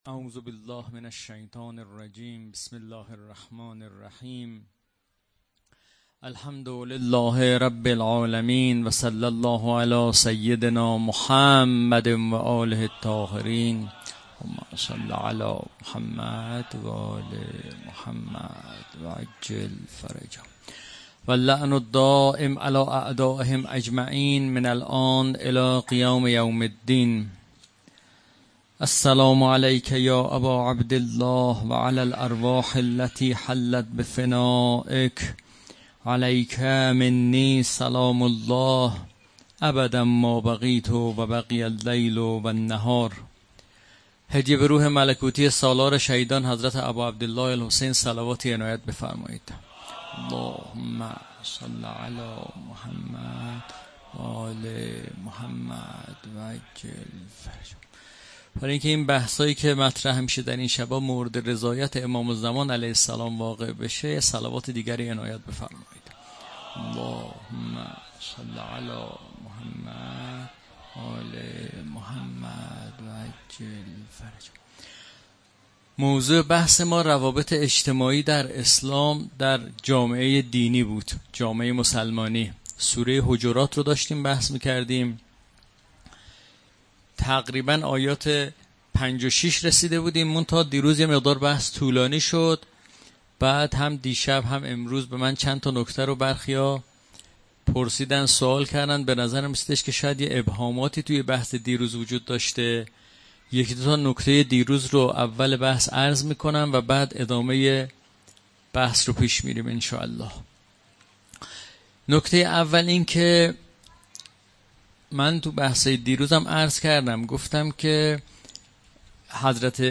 محرم امسال (۱۴۴۵ قمری؛ تیر- مرداد ۱۴۰۲ شمسی) در هیات عاشقان مهدی در شاهرود هستم و قرار است درباره ارتباطات اجتماعی در جامعه دینی و شناخت مومن واقعی بحث شود با تاکید بر سوره حجرات.